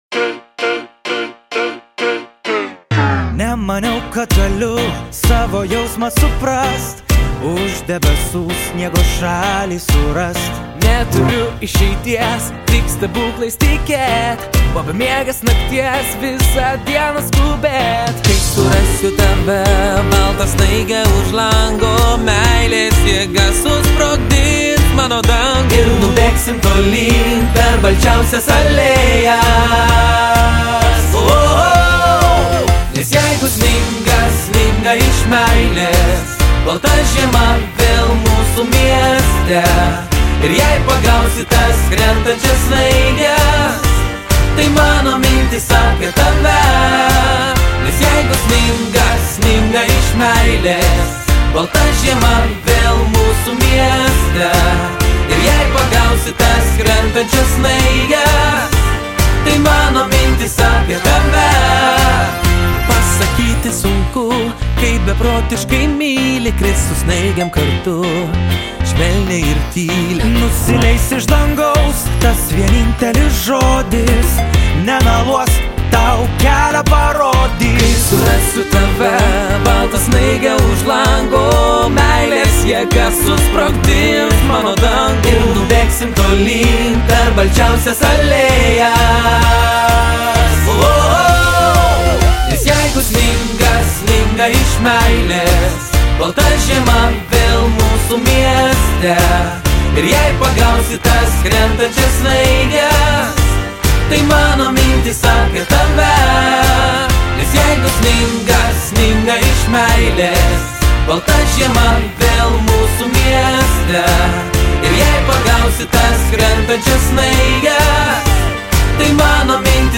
kalėdinę dainą